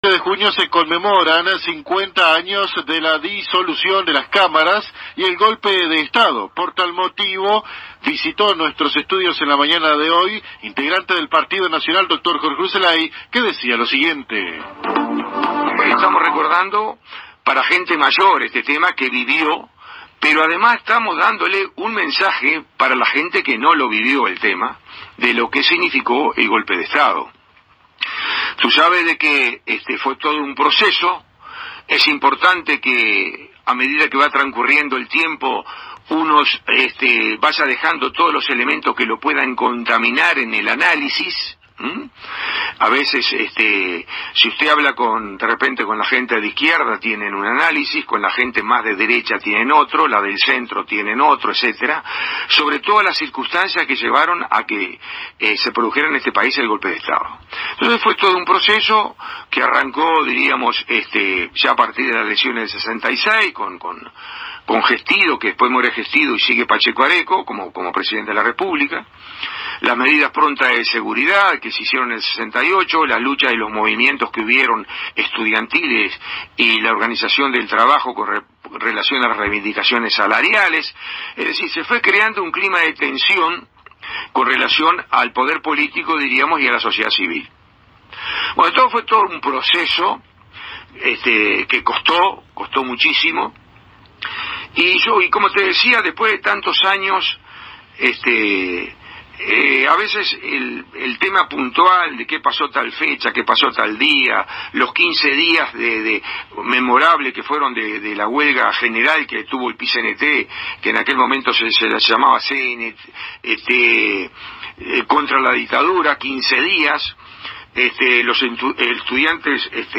De visita en los estudios de la AM 1110 de nuestra ciudad